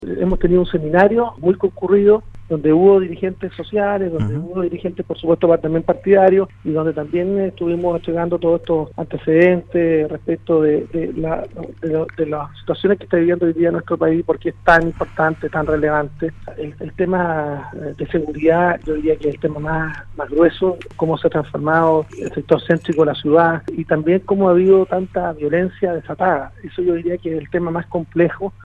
En conversación con Radio Sago, el senador de la República, Francisco Chahúan explicó parte de su visita a la zona en el marco del proceso constituyente que se realiza a nivel nacional.